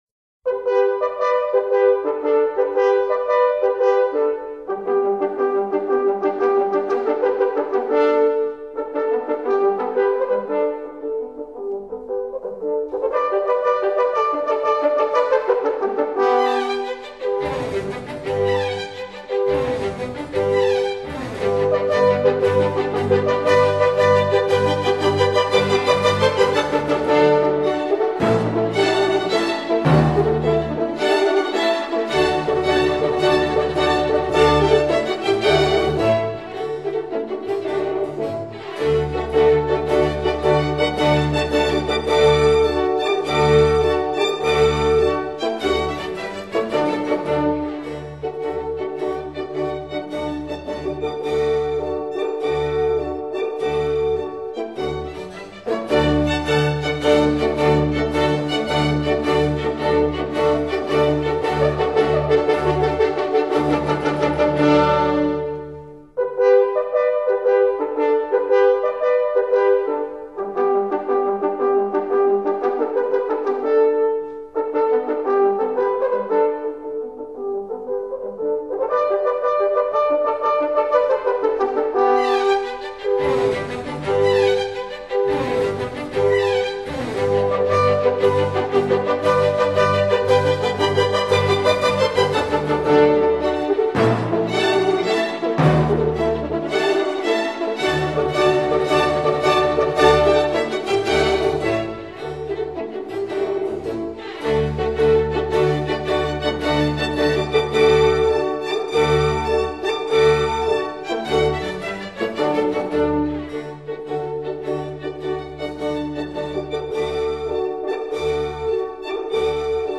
in G major for 4 Horns, Strings, Timpani and Basso Continuo
horn